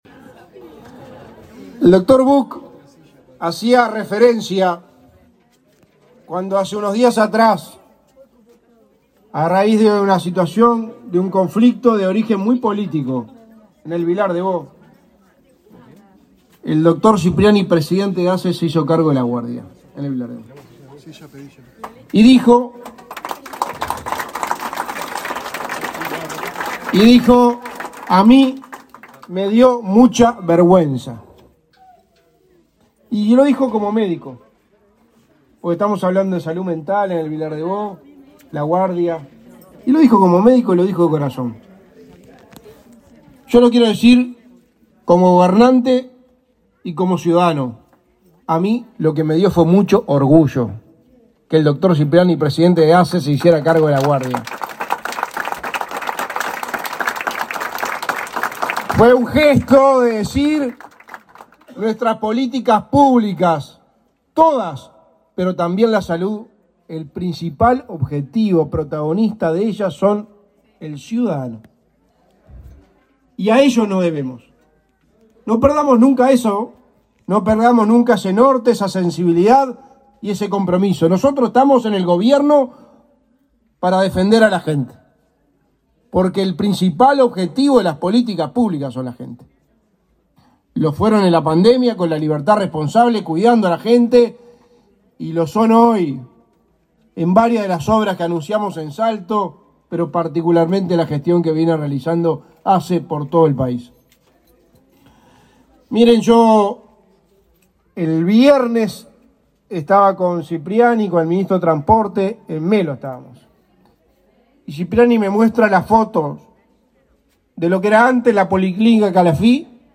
Palabras de autoridades en inauguración de ASSE en Salto